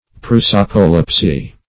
Search Result for " prosopolepsy" : The Collaborative International Dictionary of English v.0.48: Prosopolepsy \Pros`o*po*lep"sy\, n. [Gr.
prosopolepsy.mp3